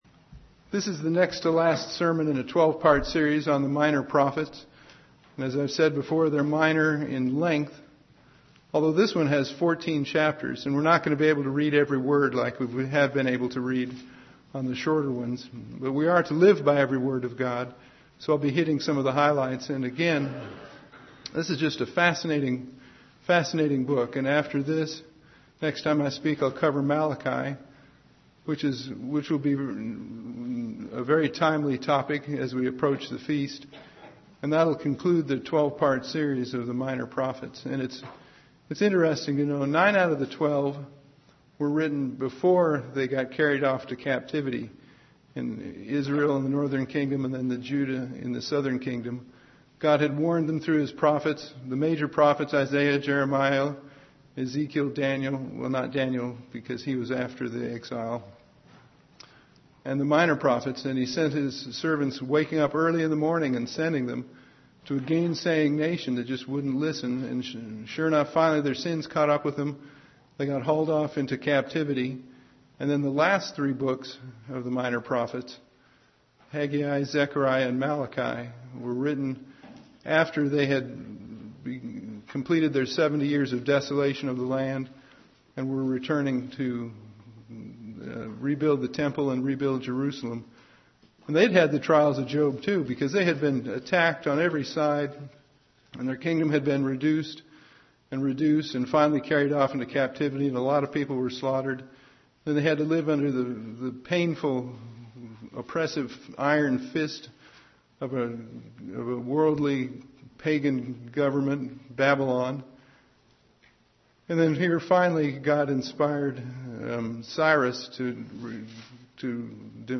The eleventh in a 12-part series of sermons on the Minor Prophets.
Given in Ft. Wayne, IN